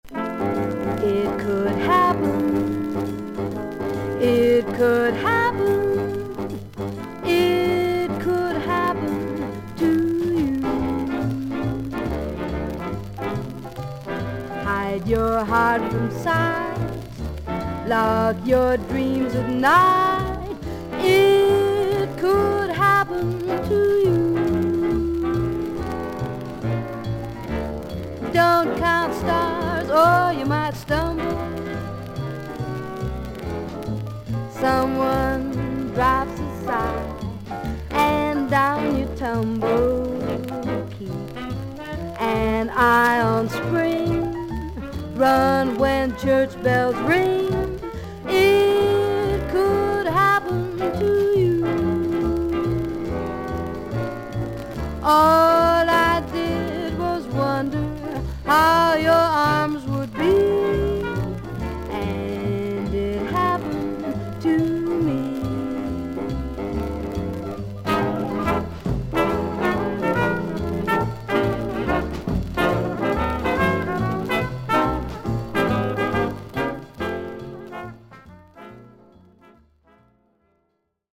女性ジャズ・シンガー。クールで愛らしい希少なクリア・ヴォイス。
VG+ 少々軽いパチノイズの箇所あり。少々サーフィス・ノイズあり。